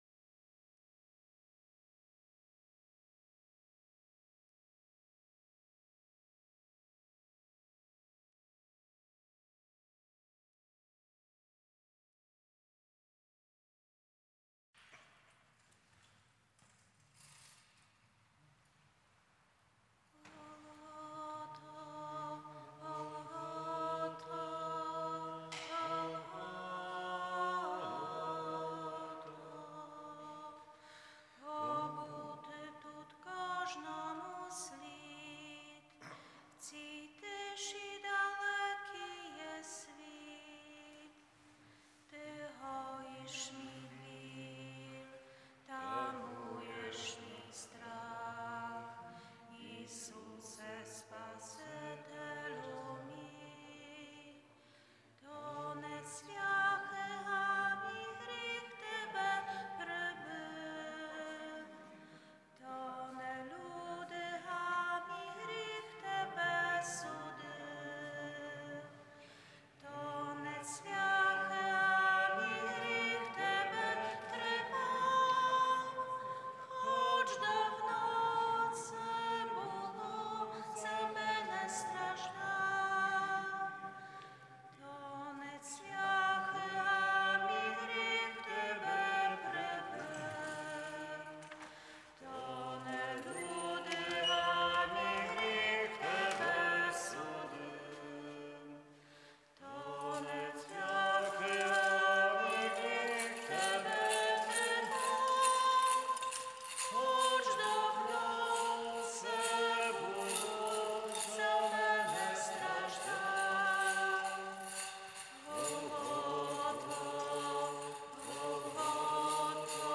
З нашої церкви – Неділя 29 Березня 2020 Божественна Літургія Василя Великого